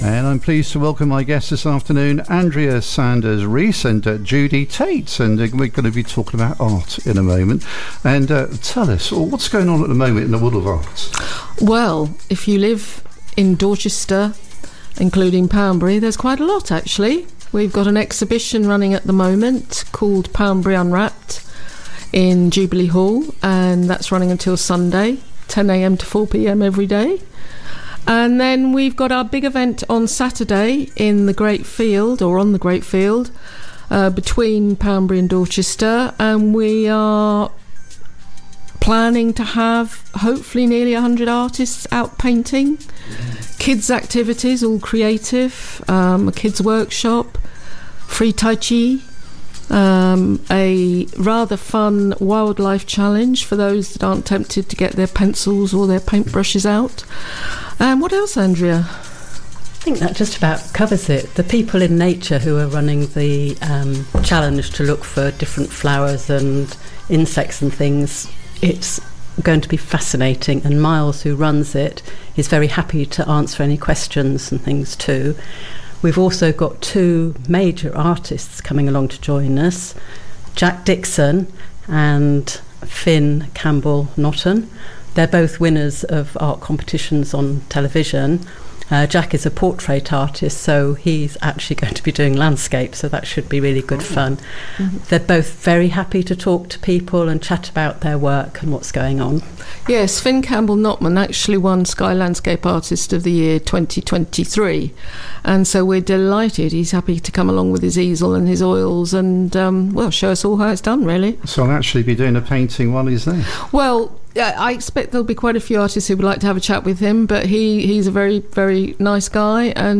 in the KeeP 106 studio